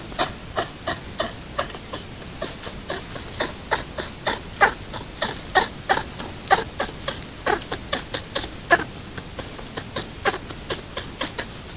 A hedgehog snorting and quacking/clucking while backing away from another individual.
hedgehog_cluck.mp3